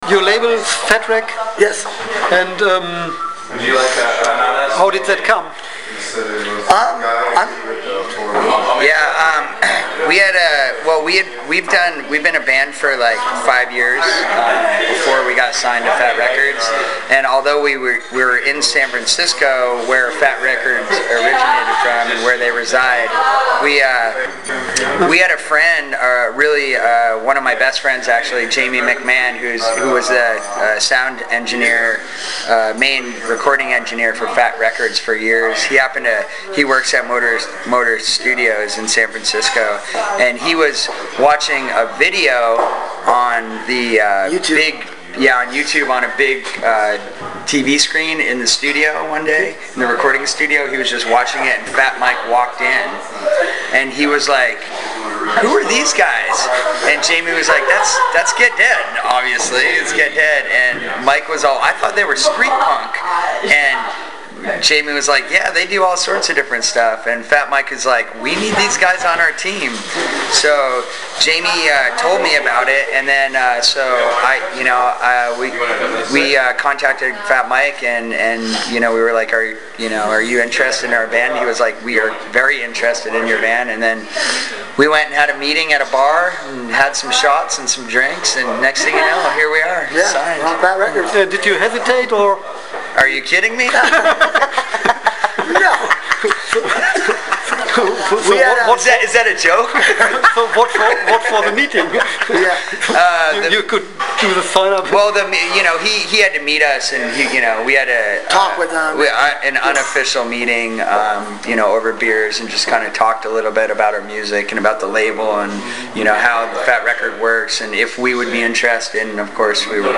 Letzte Episode Get Dead 22. August 2013 Nächste Episode download Beschreibung Teilen Abonnieren Ich hatte die Gelegenheit die Band vor ihrem Auftritt im Treff in Witten am 23. August 2013 zu interviewen.